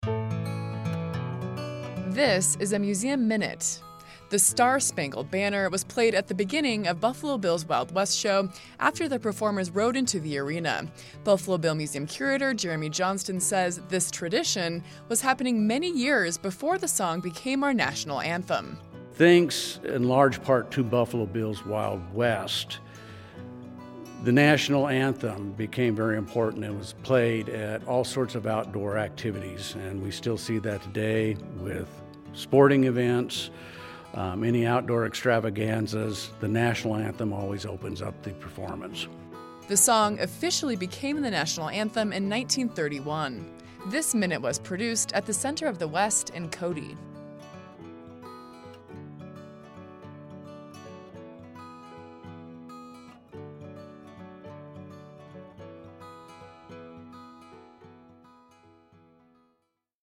A 1-minute audio snapshot highlighting a museum object from the collection of the Buffalo Bill Center of the West.